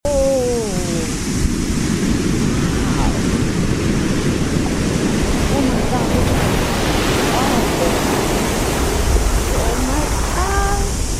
500 Meter Wave Destroys Coastal Town sound effects free download